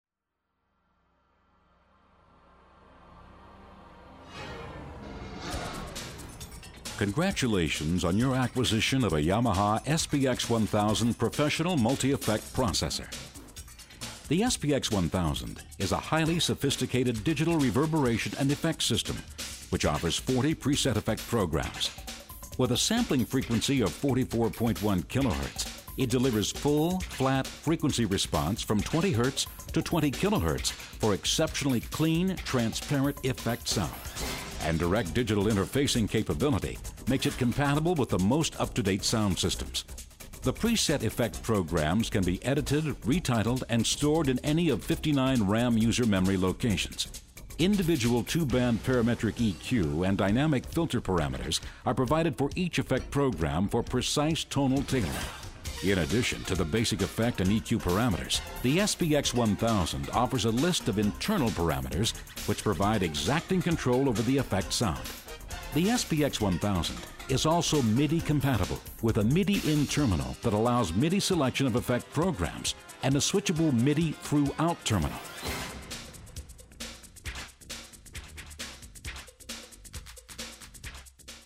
Male American V.O. talent. Warm, Deep, Big, Smooth
Sprechprobe: eLearning (Muttersprache):